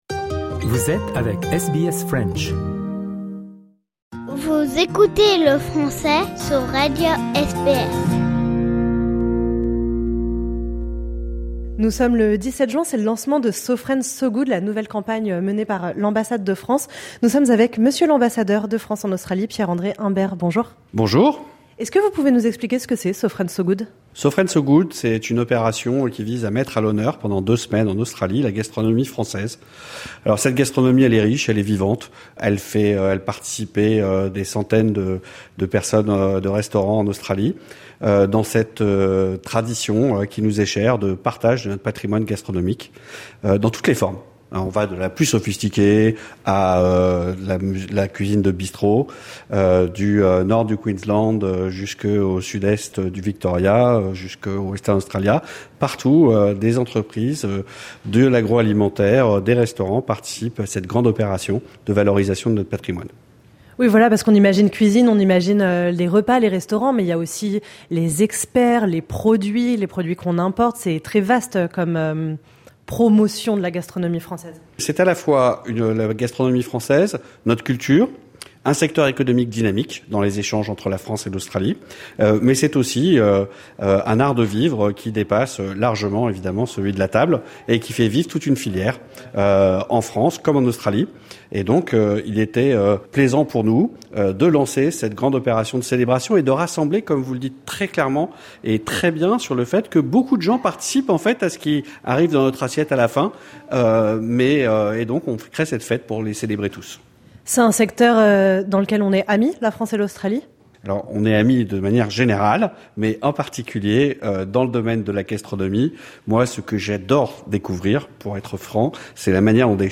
A l’occasion du lancement de la campagne So France So Good 2025, qui vise à mettre à l’honneur la gastronomie française en Australie, l’ambassadeur de France en Australie, Pierre-André Imbert nous parle des liens culinaires entre la France et l’Australie.